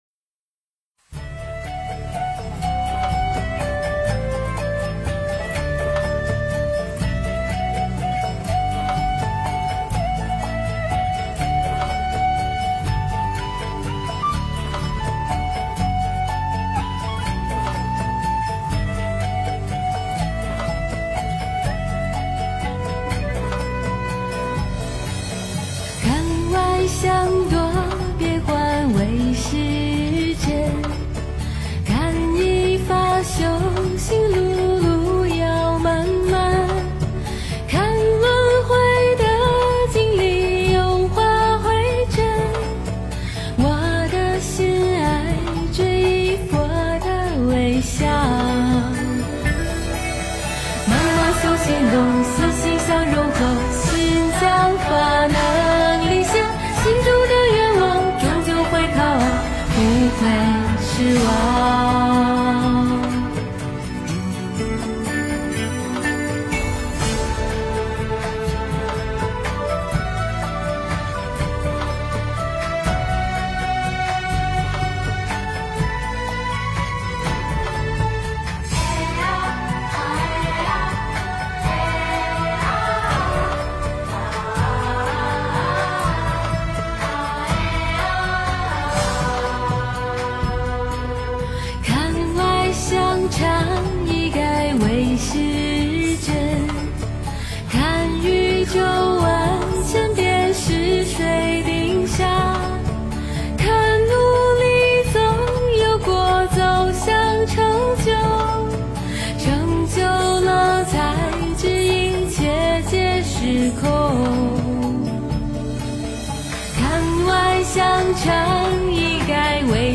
无为 - 诵经 - 云佛论坛
无为 诵经 无为--佛教音乐 点我： 标签: 佛音 诵经 佛教音乐 返回列表 上一篇： 无边 下一篇： 喜欢佛的圣号 相关文章 63.心的秘密--佚名 63.心的秘密--佚名...